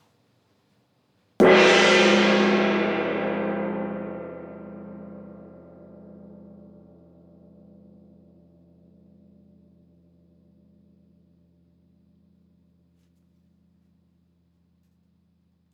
petit-fort-haut.wav